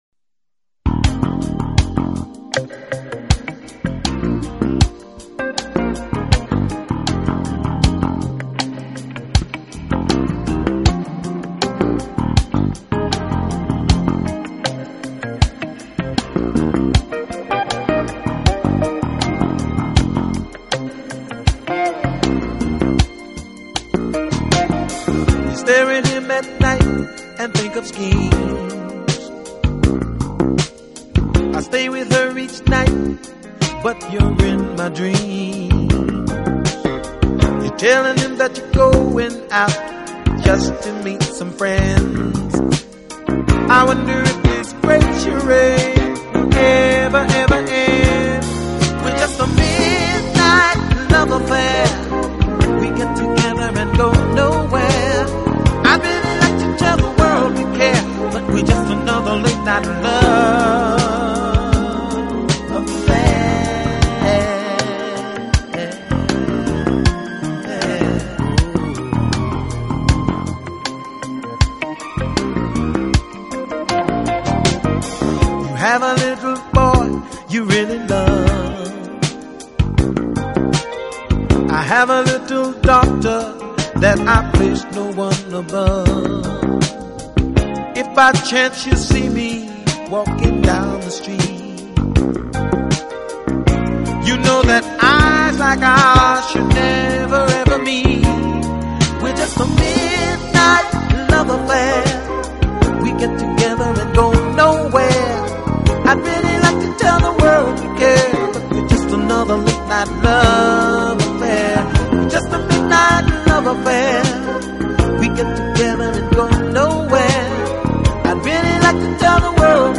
一套精彩的爵士吉他合辑，荟萃了当今众多艺术名家，集爵士吉他之精华。